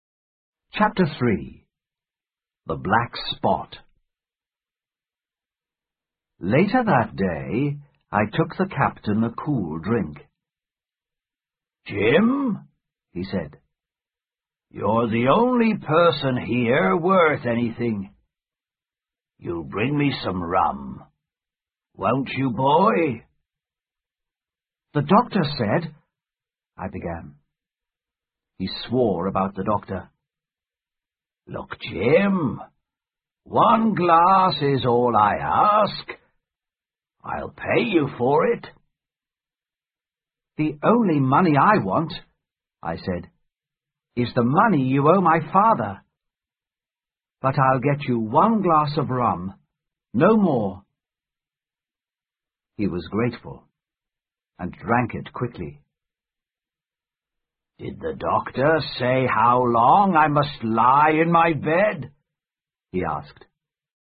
在线英语听力室《金银岛》第三章 黑券(1)的听力文件下载,《金银岛》中英双语有声读物附MP3下载